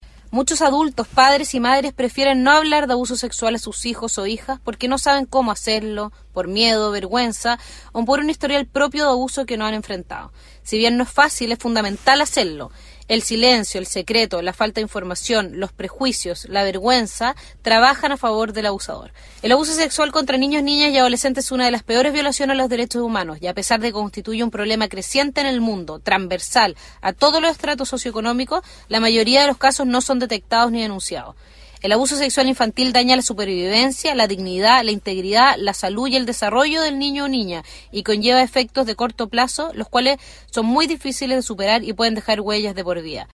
En Castro se realizó el seminario denominado “El Peor Abuso”